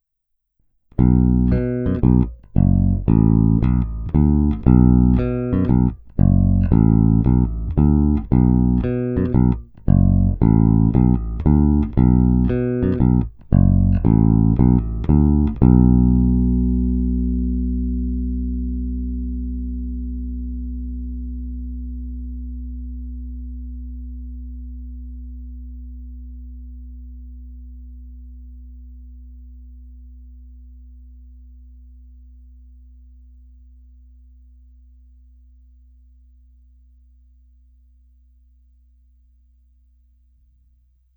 Baskytara má dostatek středů umožňujících jí se prosadit v kapele a zároveň tmelit zvuk.
Není-li uvedeno jinak, následující nahrávky jsou provedeny rovnou do zvukové karty, jen normalizovány, jinak ponechány bez úprav.
Oba snímače